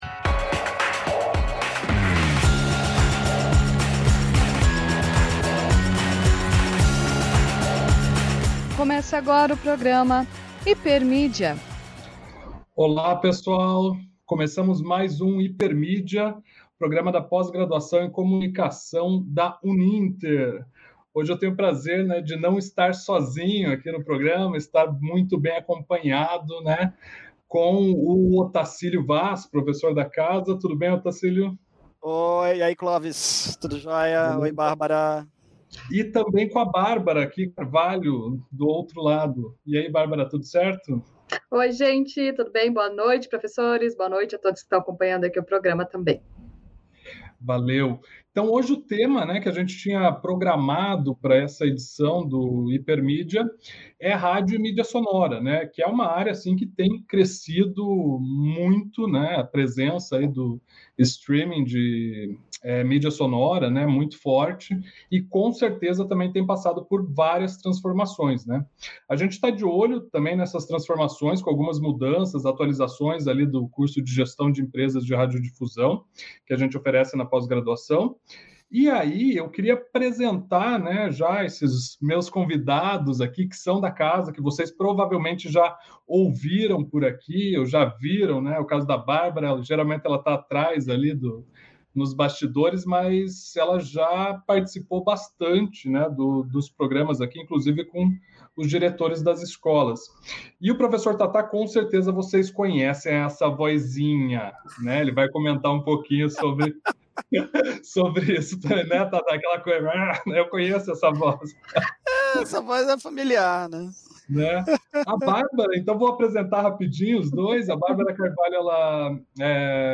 O episódio da vez do Hipermídia é especial para os amantes do som, pois vamos falar sobre “rádio e mídias sonoras”. Para o bate-papo